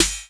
cch_07_snare_one_shot_high_snap_cutter.wav